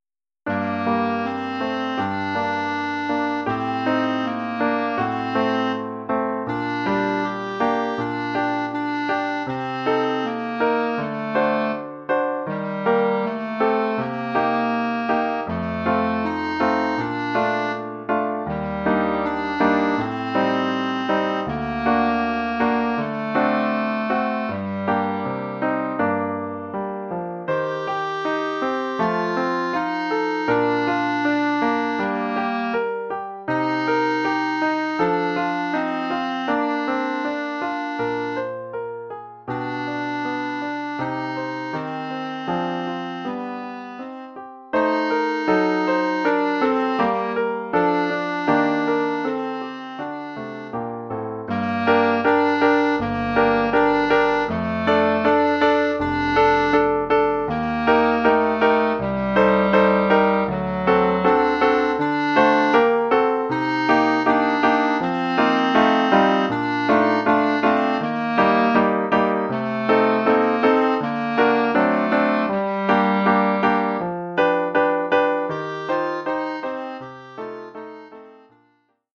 Oeuvre pour clarinette et piano.
Niveau : débutant.